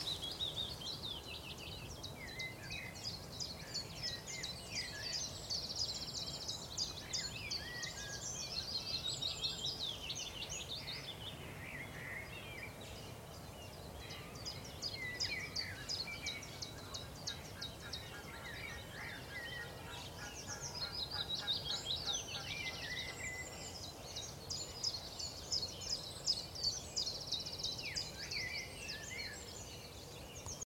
Mosquitero Musical (Phylloscopus trochilus)
On the recording both the willow warbler and the common chiffchaff are heard, apart from other birds in the background.
Localización detallada: Ackerdijkse Plassen
Certeza: Vocalización Grabada